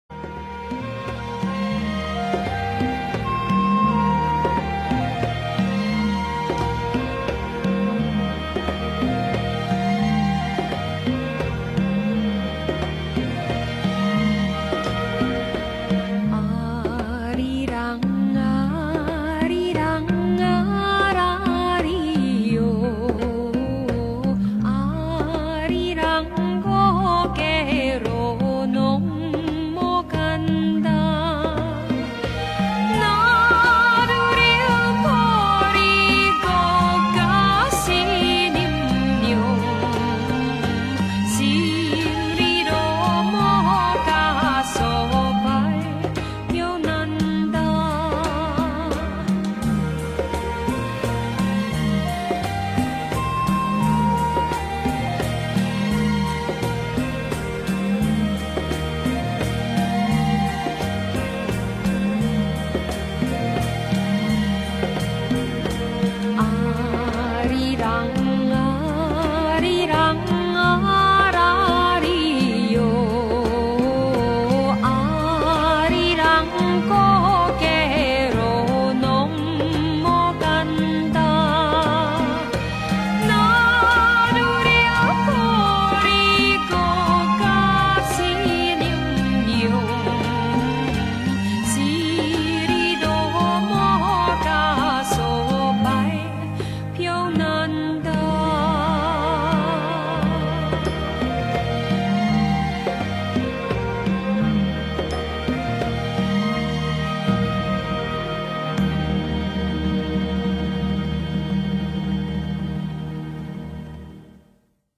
Popular Korean Folk Song